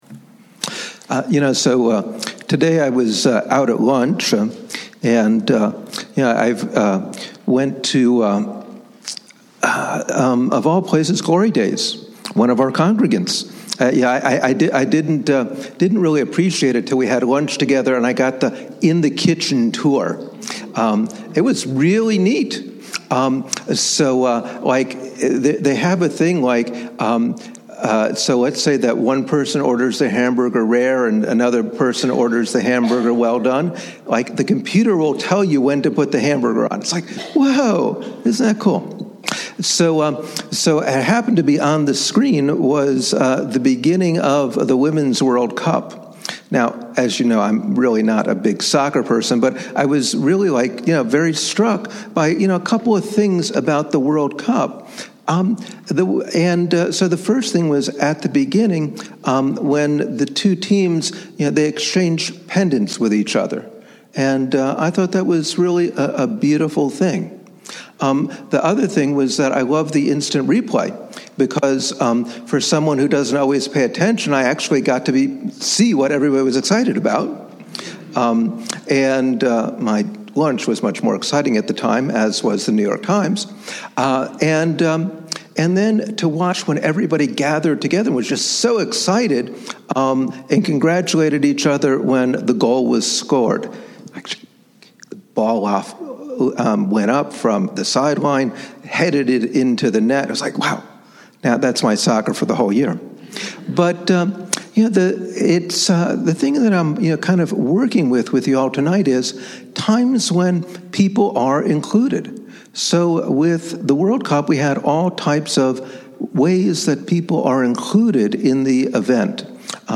Shabbat Worship Sermon – June 7, 2019